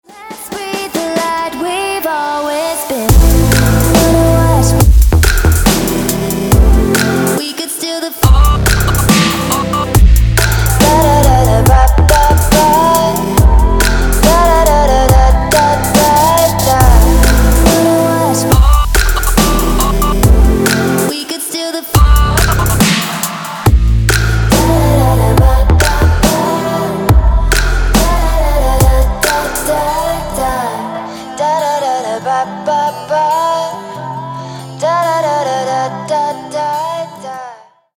• Качество: 224, Stereo
Electronic
Trap
club